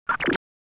sound_drop_splat.wav